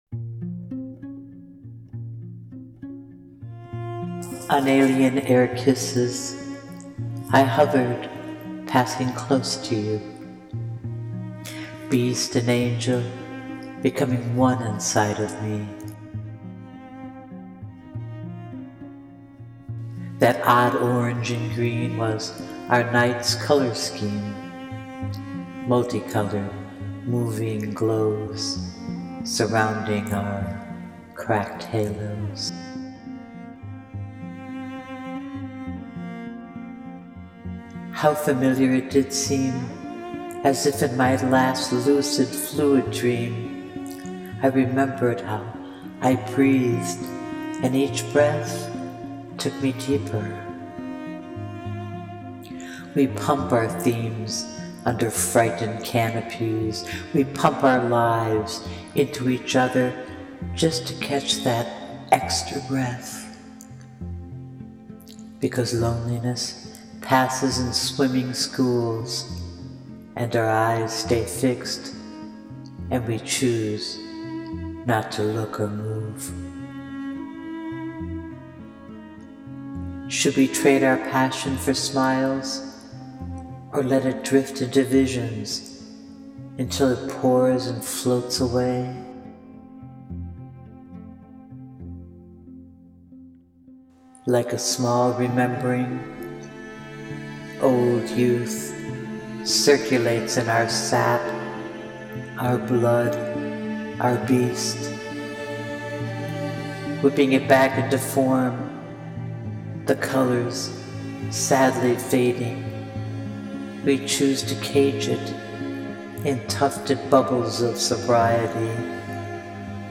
Click Play to hear my recital to the haunting cello music of David Darling’s “Minor Blue.”
It almost sounded like you were underwater.
Yes, i added some fx to my voice to try and give it a quasi underwater feeling,,You are so perceptive!!